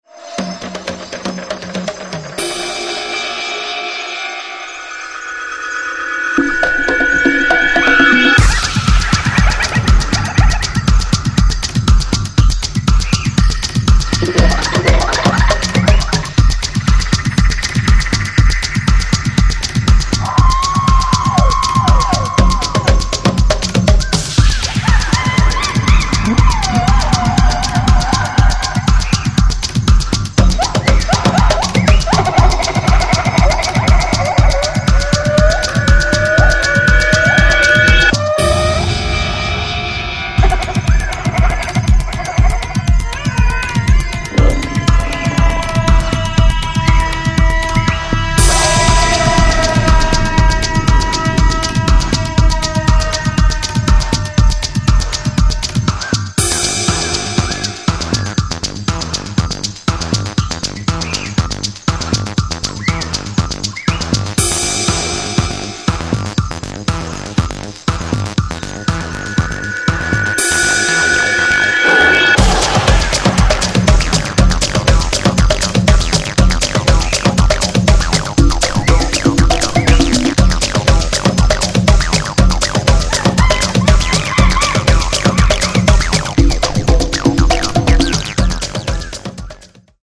パーカッションや動物の鳴き声、FXの応酬で攻めるアシッド・ストンパー
ニューウェイヴ/EBM/アシッド・ハウス/トライバル等の要素を退廃的に昇華した、濃厚なデビュー作となっています。